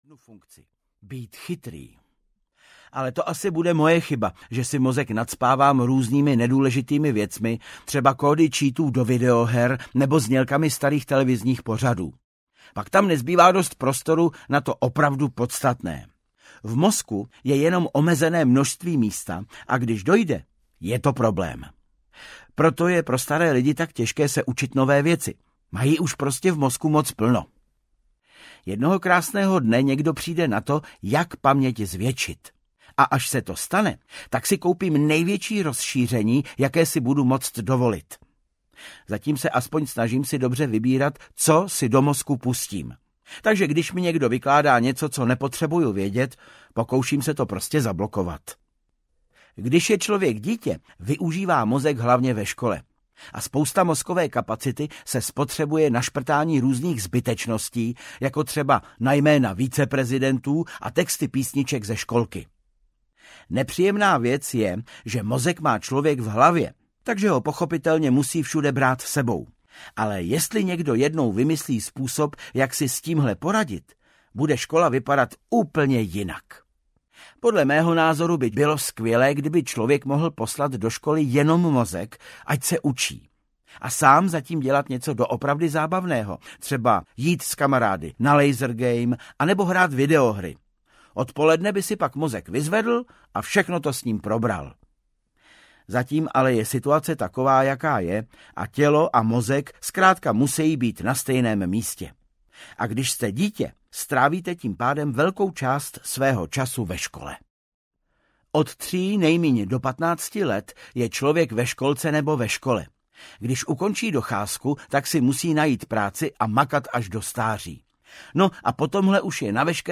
Deník malého poseroutky 18 audiokniha
Ukázka z knihy
• InterpretVáclav Kopta